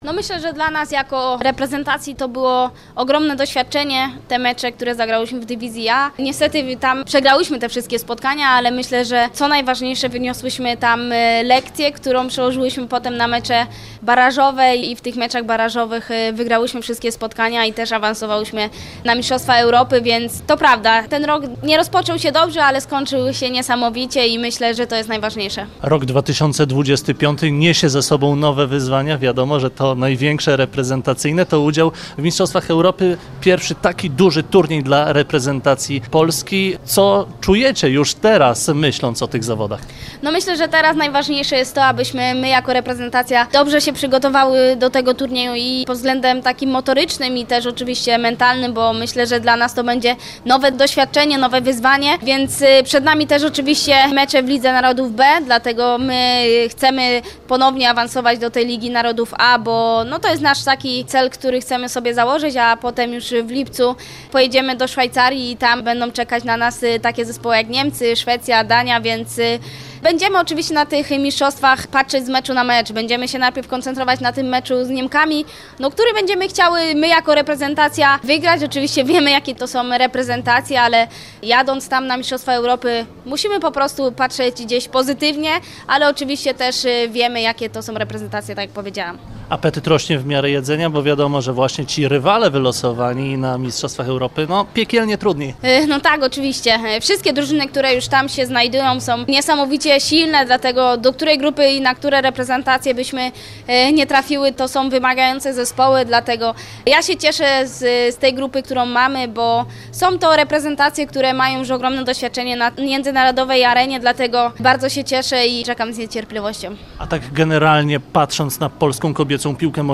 O tym co było i co czeka naszą reprezentację kobiet mówi liderka naszej kadry Ewa Pajor (na zdj.).